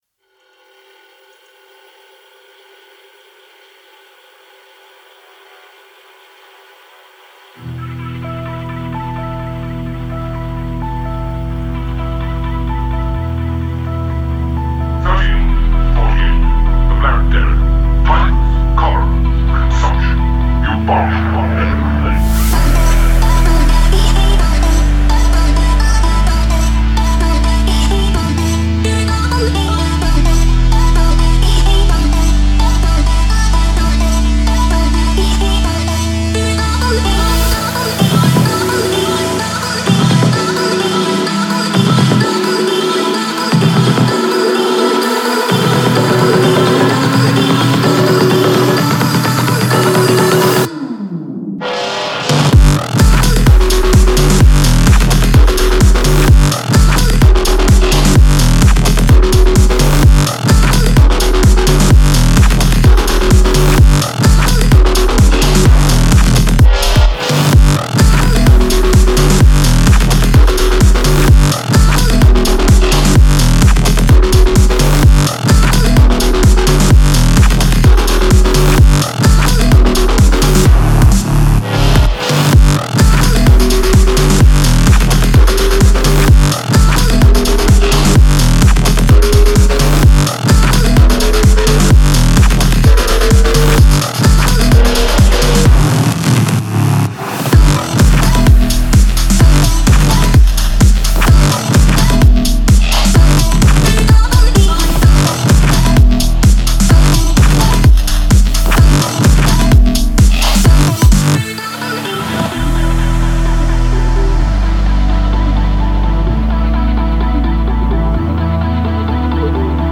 BPM128-128
Audio QualityPerfect (High Quality)
Full Length Song (not arcade length cut)